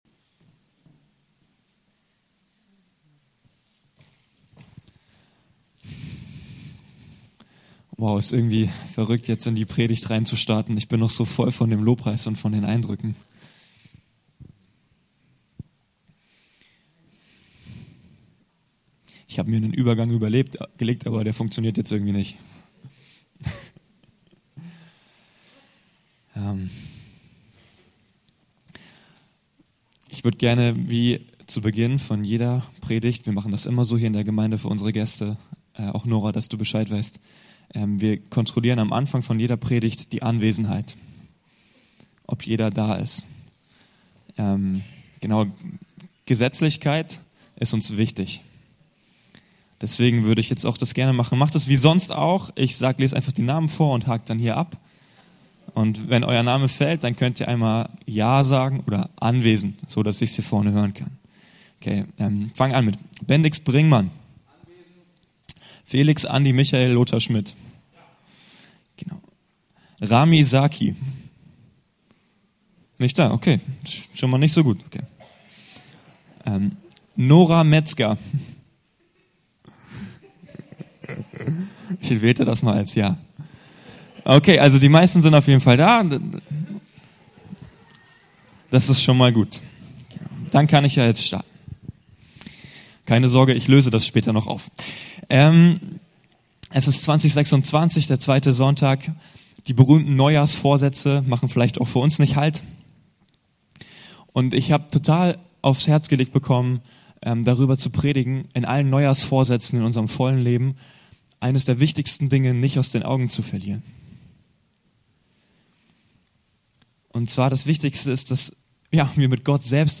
Im Glauben bleiben - in Gemeinschaft bleiben ~ Anskar-Kirche Hamburg- Predigten Podcast